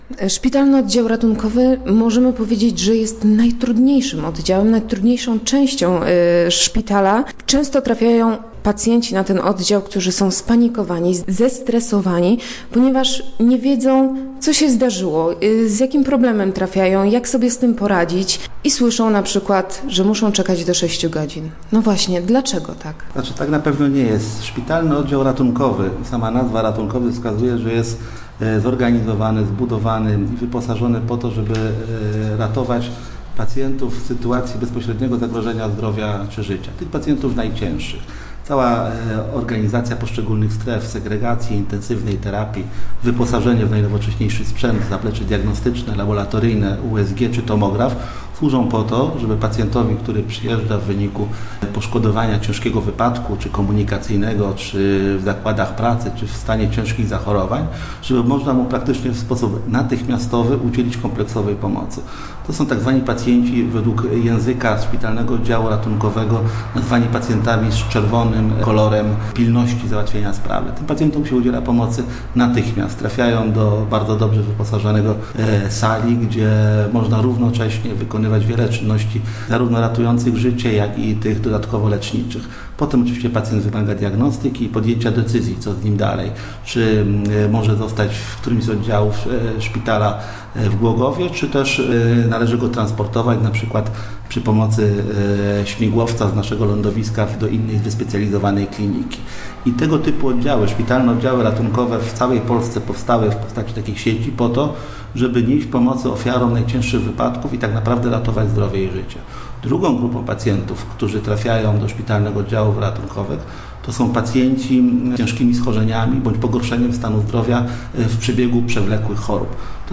Start arrow Rozmowy Elki arrow Jak działa głogowski SOR?